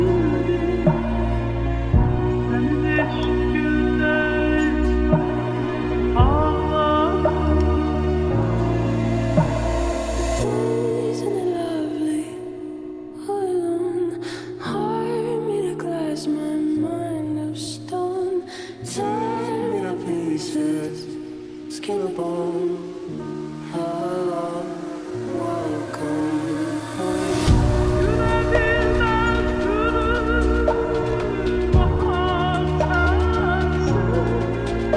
Жанр: Рок / Фолк / Кантри